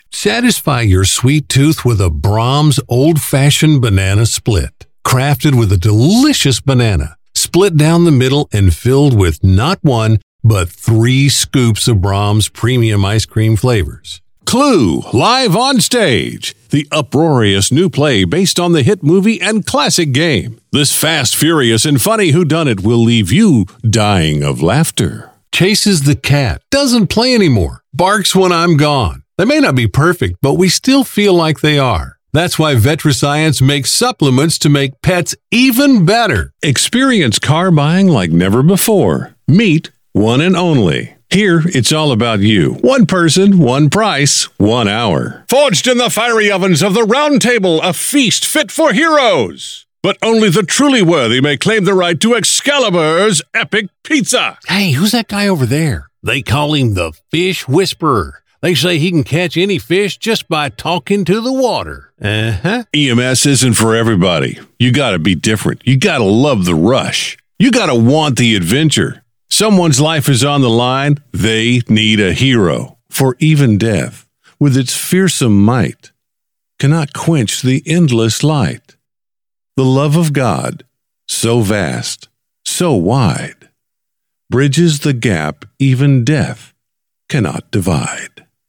Male
Adult (30-50), Older Sound (50+)
Conversational, natural, guy-next-door reads that are believable, upbeat, and a real person.
Radio Commercials
Radio Commercial Variety